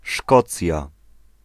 Ääntäminen
Synonyymit Albany Ääntäminen US UK : IPA : /ˈskɒt.lənd/ US : IPA : /ˈskɑt.lənd/ Scotland: IPA : /ˈskɔʔ.lənd/ North England: IPA : /ˈskɔʔ.lənd/ Lyhenteet ja supistumat (laki) Scot.